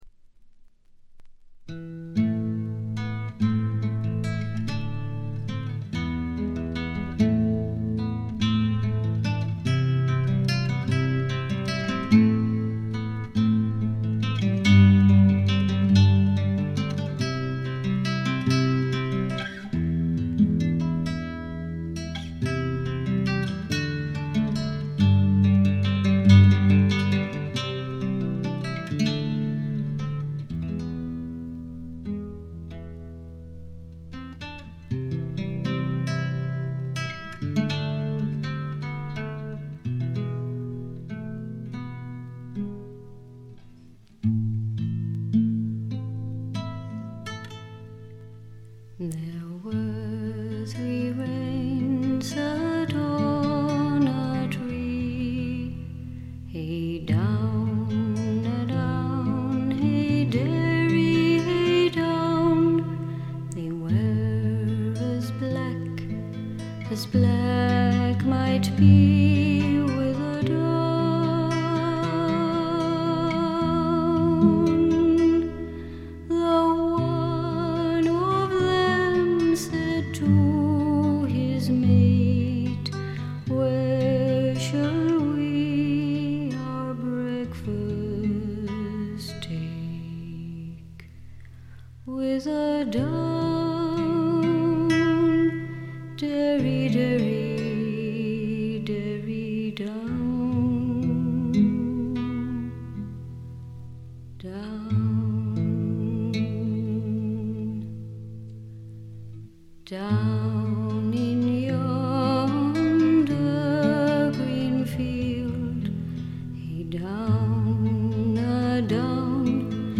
メランコリックな曲が多く彼女のヴォーカルは情感を巧みにコントロールする実に素晴らしいもの。
試聴曲は現品からの取り込み音源です。
Vocals, Harp [Irish]
Recorded & mixed At Hollywood Studios, Rome, April 1983.